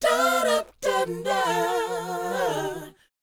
DOWOP C 4D.wav